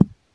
beeb kick 14
Tags: 808 drum cat kick kicks hip-hop